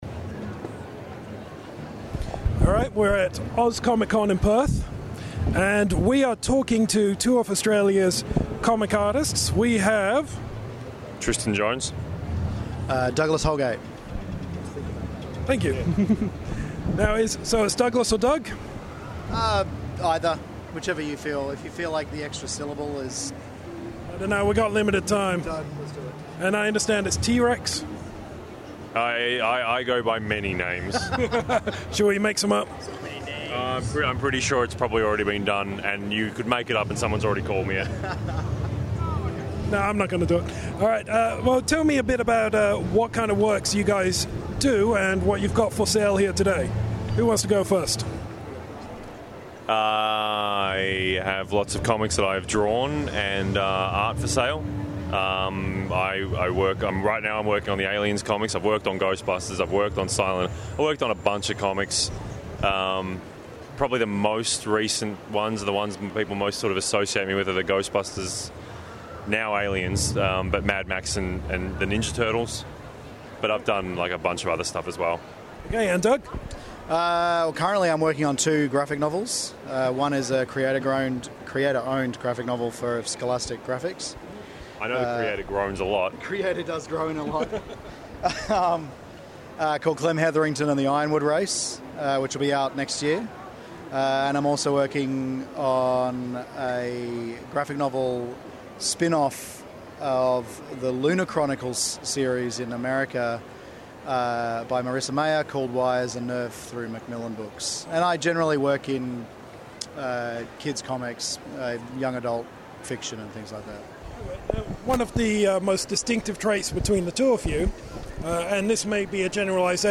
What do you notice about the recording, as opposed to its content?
Click below to enjoy the audio complete with Convention ambience!